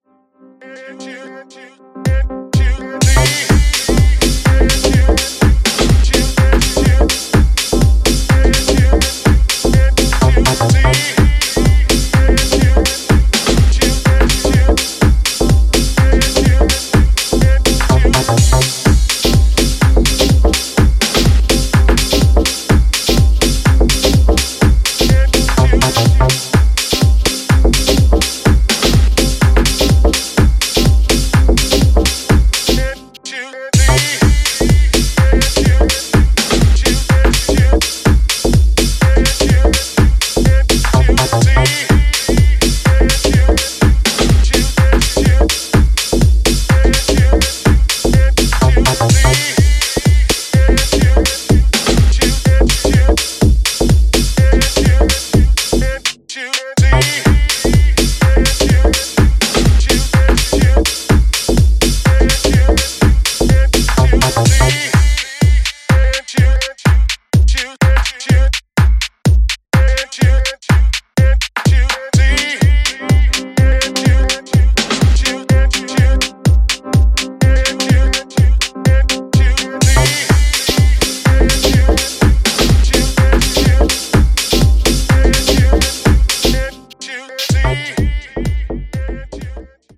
Deep house
Soulful house